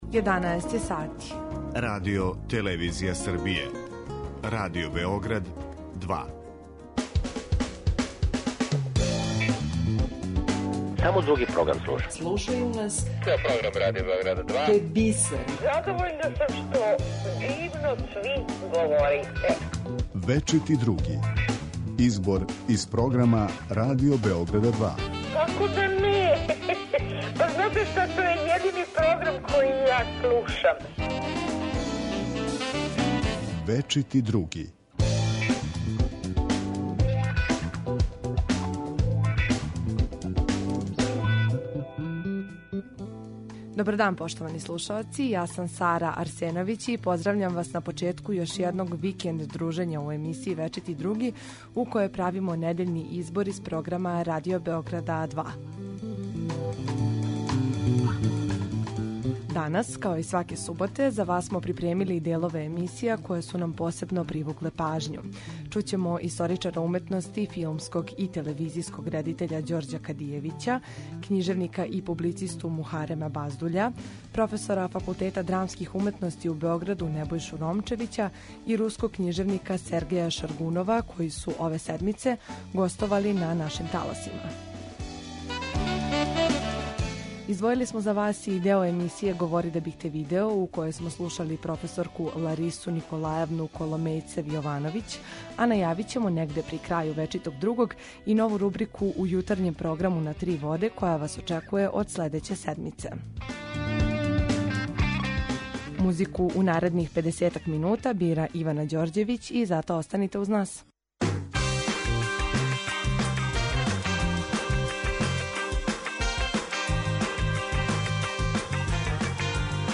Избор из програма Радио Београда 2
У данашњој емисији чућемо историчара уметности, филмског и телевизијског редитеља Ђорђа Кадијевића, книжевника и публицисту Мухарема Баздуља, професора Факултета драмских уметности у Београду, Небојшу Ромчевића и руског књижевника Сергеја Шаргунова, који су ове седмице гостовали на нашим таласима.